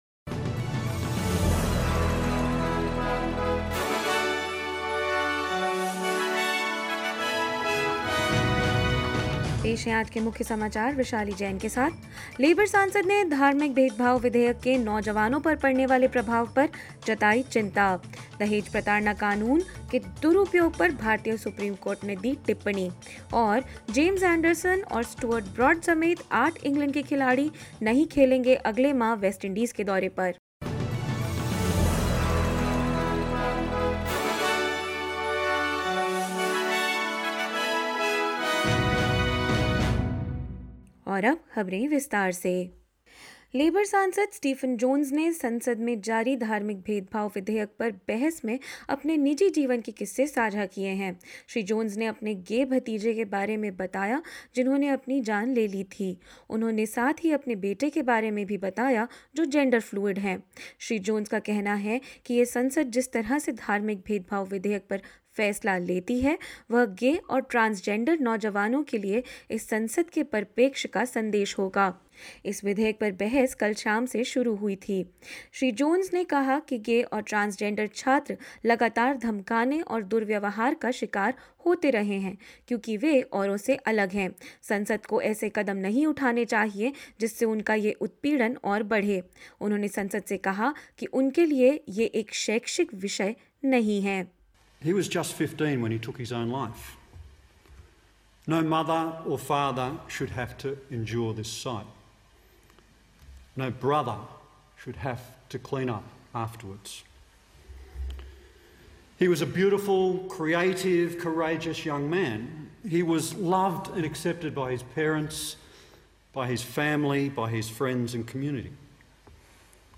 SBS Hindi News 09 February 2022: Labour expresses concerns over impact of Religious Discrimination Bill on youth